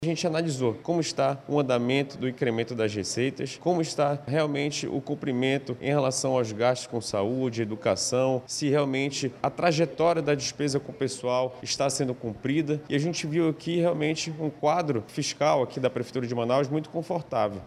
O vereador Marco Castilhos, do União Brasil, integrante da Comissão de Finanças, destacou os critérios que foram avaliados para atestar ou não o equilíbrio das contas públicas.